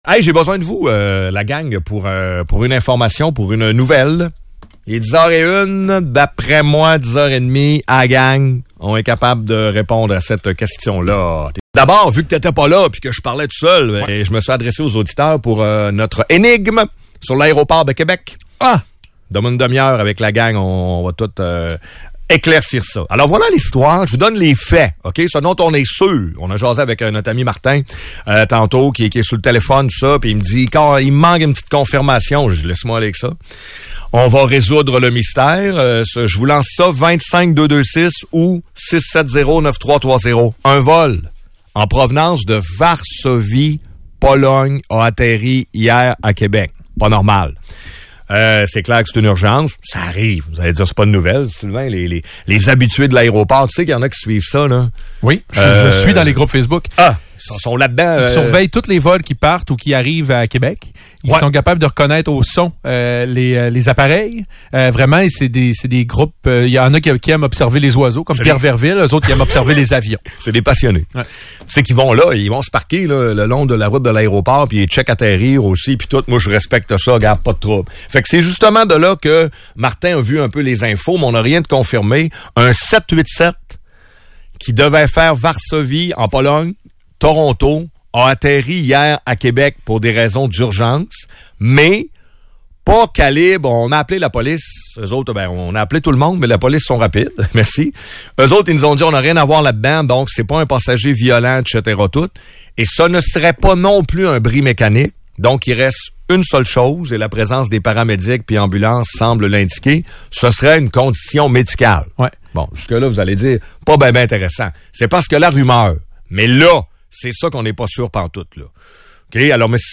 Voici un segment radiophonique à ce sujet: